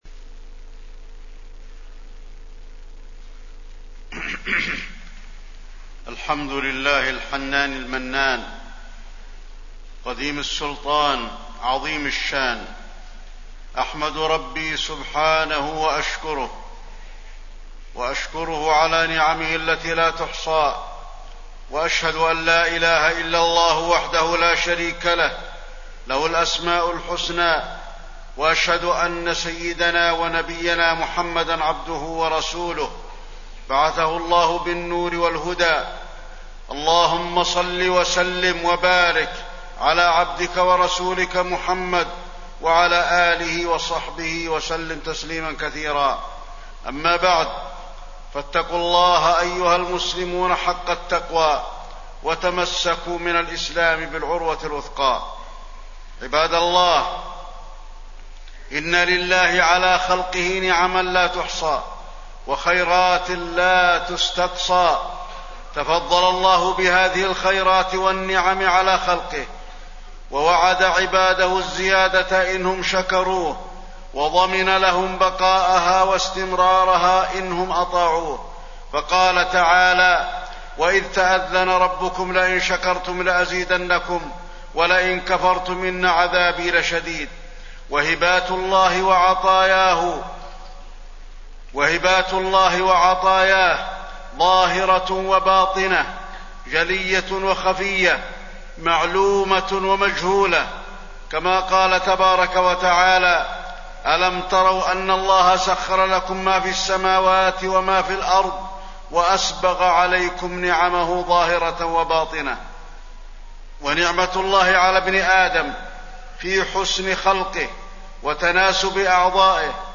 تاريخ النشر ٢٩ محرم ١٤٣١ هـ المكان: المسجد النبوي الشيخ: فضيلة الشيخ د. علي بن عبدالرحمن الحذيفي فضيلة الشيخ د. علي بن عبدالرحمن الحذيفي نعم الله ووجوب الشكر